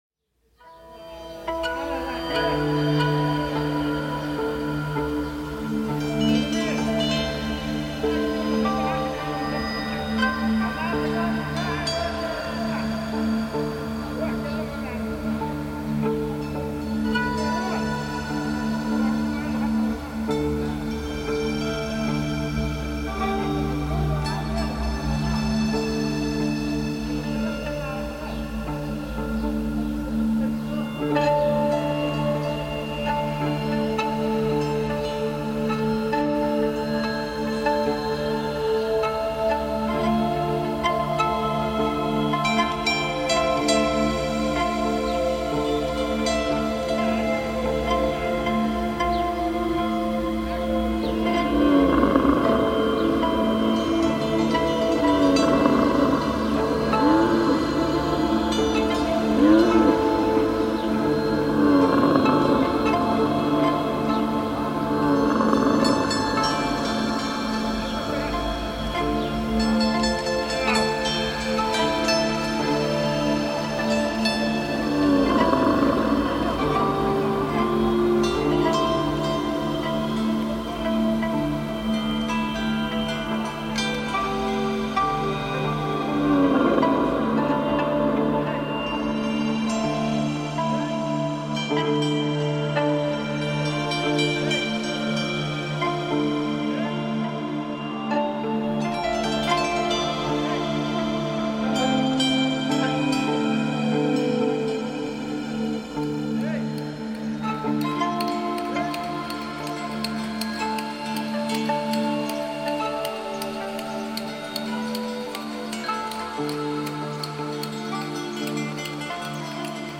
Temple of Hapshetsut, Luxor reimagined